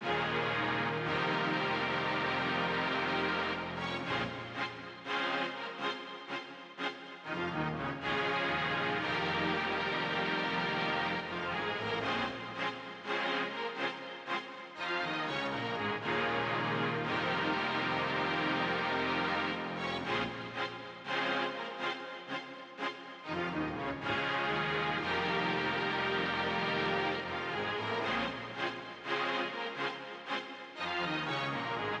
13 strings A.wav